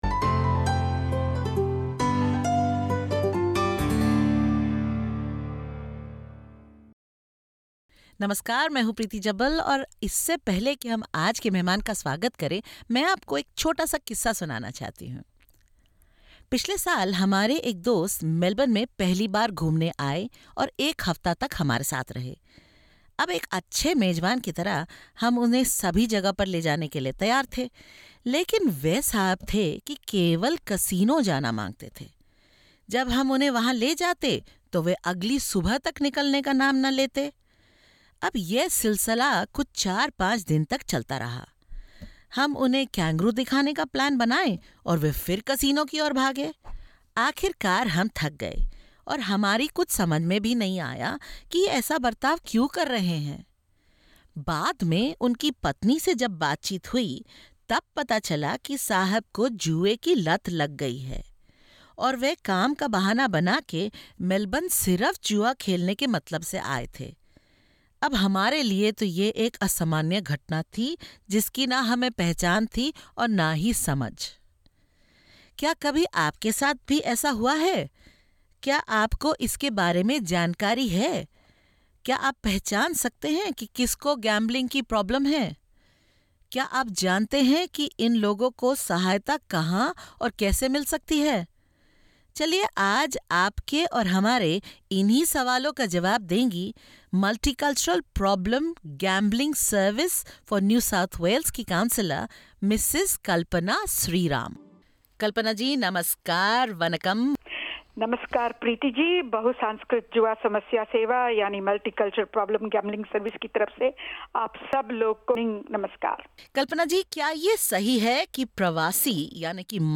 Note: We would like to inform you that the information expressed in this series/interview is of general nature.